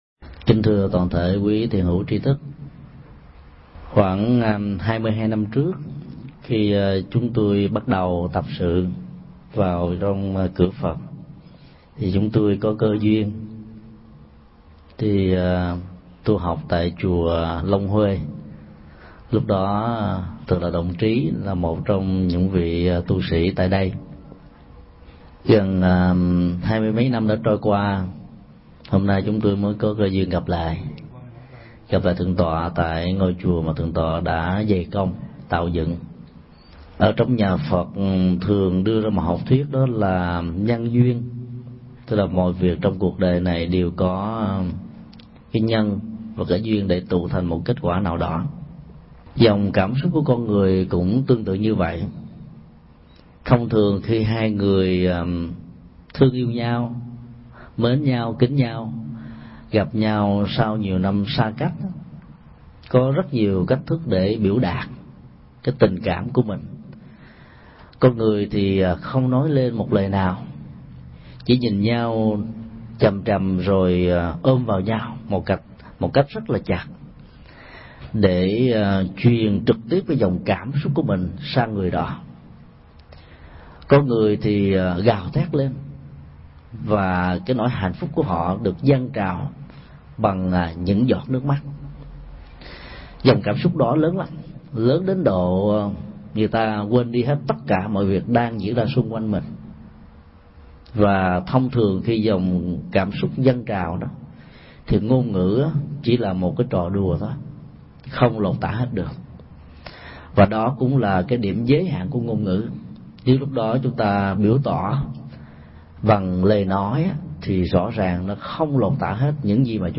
Tải mp3 Pháp Thoại Chuyển hoá cảm xúc 2 – Thầy Thích Nhật Từ Giảng tại Tu viện Viên Chiếu, Sacramento, ngày 25 tháng 6 năm 2005